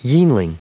Prononciation du mot yeanling en anglais (fichier audio)
Prononciation du mot : yeanling